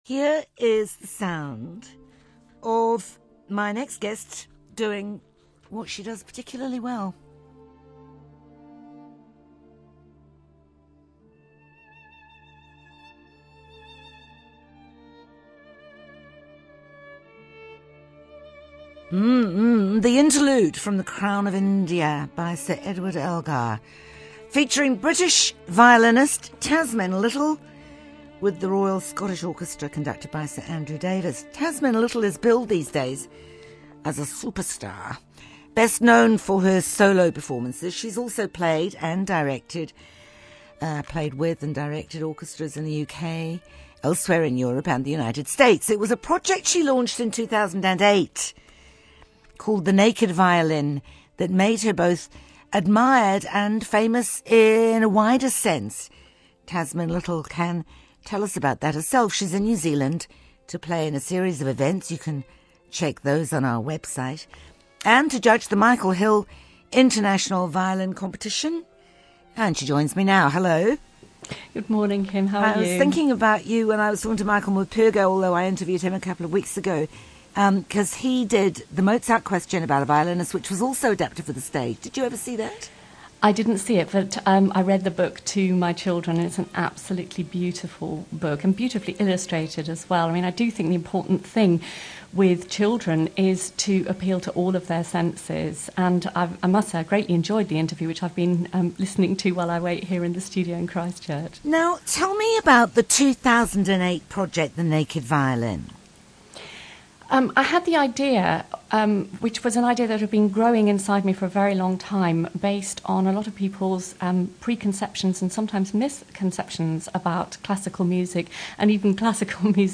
NZ RADIO NATIONAL Acclaimed British violinist who's bringing her Naked Violin project to New Zealand. (Kim Hill interview ) (duration: 11′29″) UPBEAT Interview Kim Hill Interview February 2011 RTE Lyric FM, the classical music station in the Irish Republic carried an interview with Tasmin on 26th November.